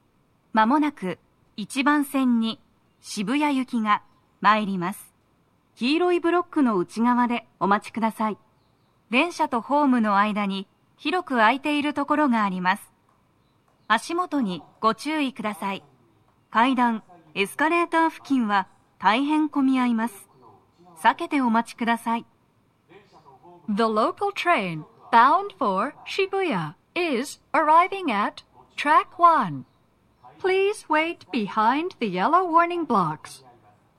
スピーカー種類 TOA天井型
🎵接近放送
鳴動は、やや遅めです。